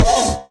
sounds / mob / horse / skeleton / hit2.mp3